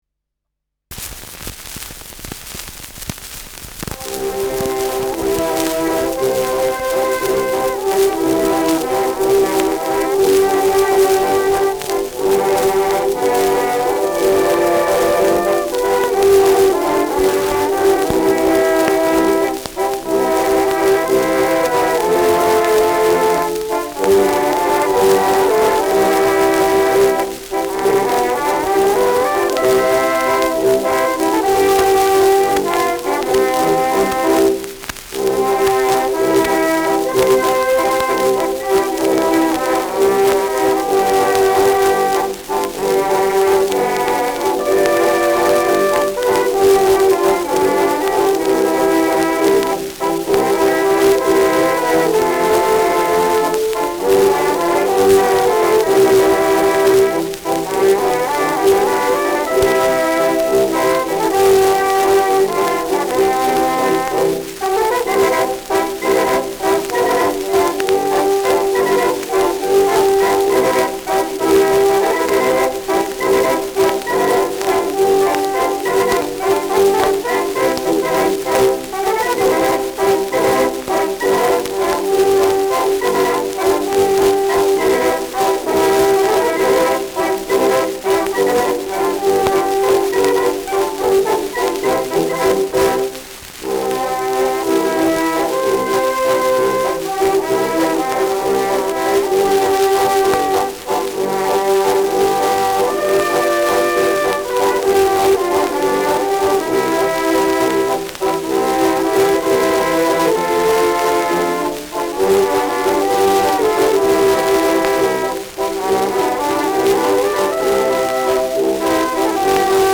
Schellackplatte
starkes Rauschen : präsentes Knistern
[Hannover] (Aufnahmeort)